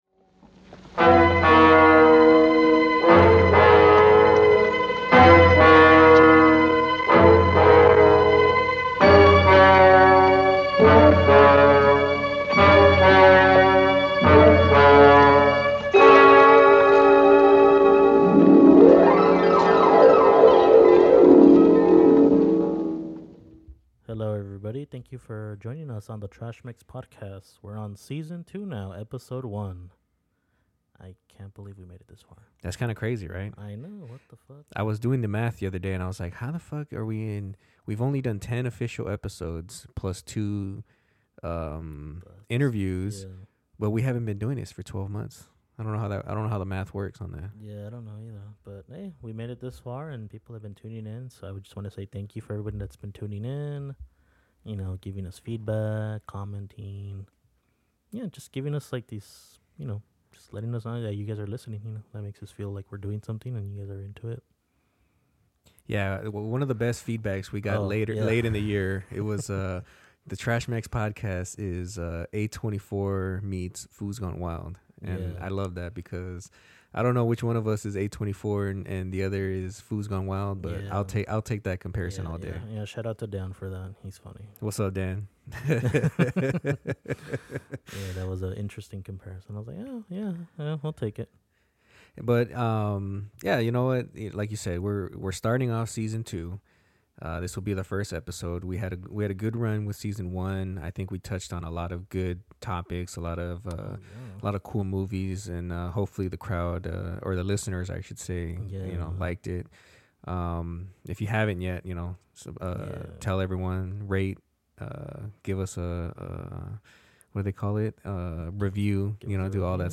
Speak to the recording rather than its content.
recorded in Southern California